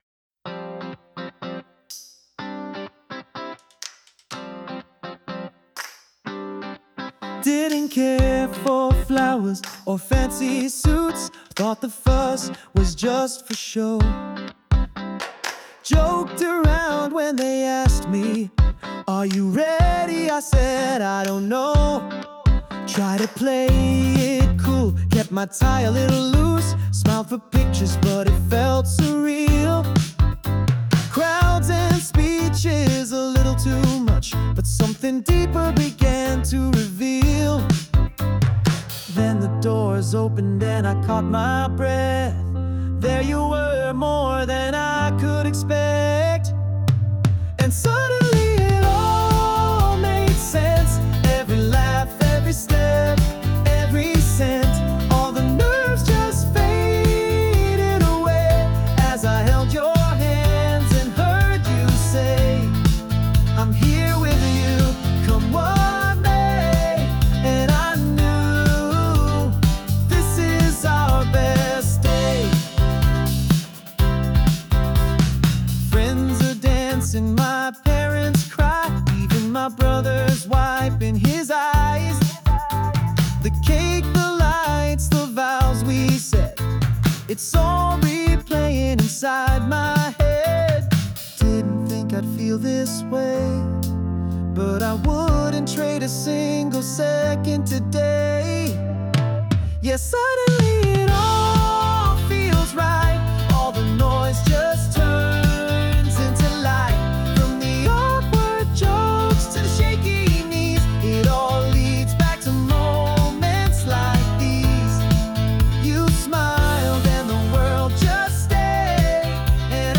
洋楽男性ボーカル著作権フリーBGM ボーカル
男性ボーカル（洋楽・英語）曲です。
シティポップの洗練されたサウンドに乗せて、